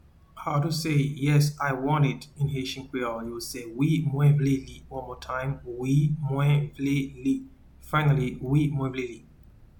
Pronunciation:
Yes-I-want-it-in-Haitian-Creole-Wi-mwen-vle-li.mp3